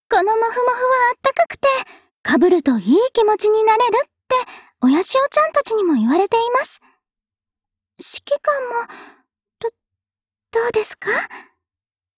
（ 留言 | 贡献 ） 协议：Copyright，人物： 碧蓝航线:长波语音 您不可以覆盖此文件。